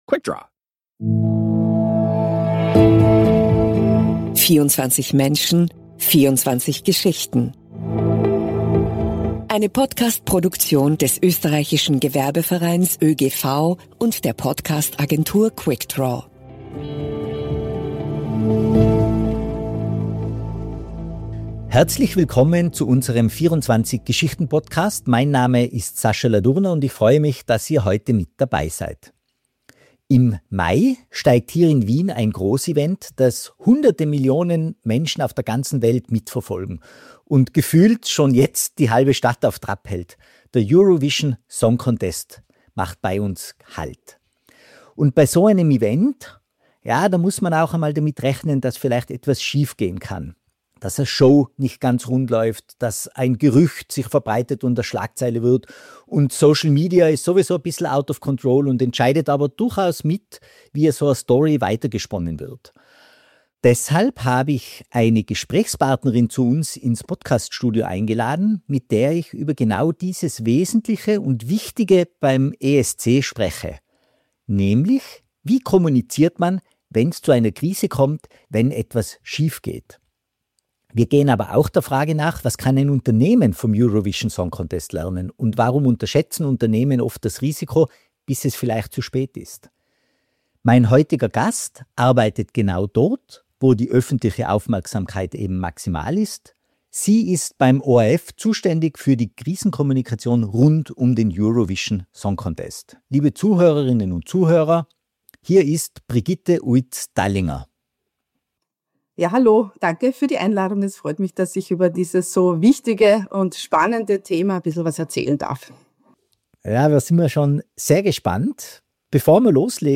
Im Gespräch geht es auch um die ersten 60 Minuten auf Social Media, den Unterschied zwischen „Inzident“ und „Krise“ und warum „Kleinheit“ kein Schutz ist. Und: Wie Vertrauen nach einer Krise realistisch wieder aufgebaut werden kann – ohne Ausreden, aber mit Verantwortung, Maßnahmen und Zeit.